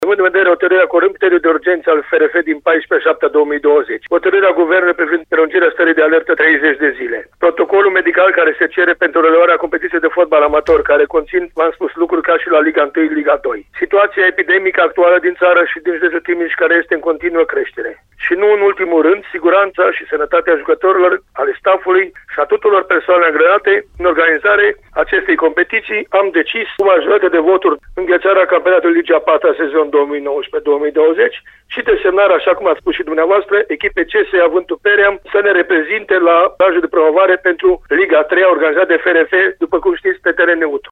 într-un interviu păentru Radio Timișoara